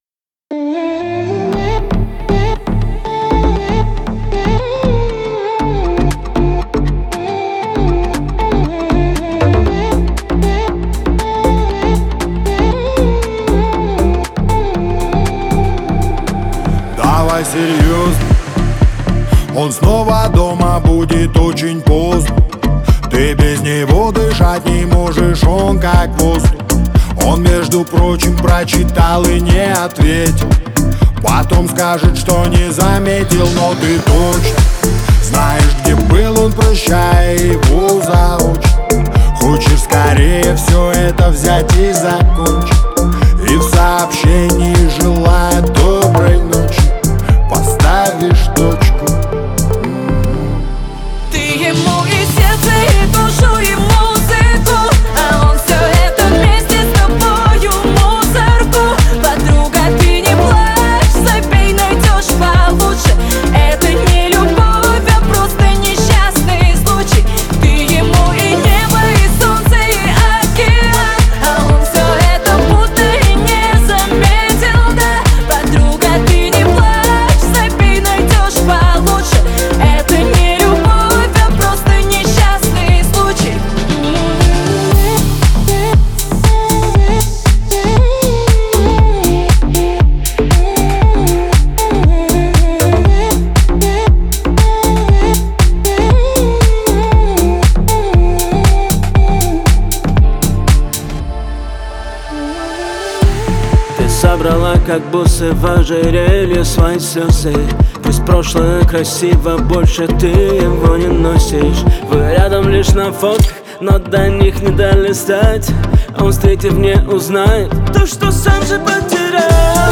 Жанр: Русские народные песни Слушали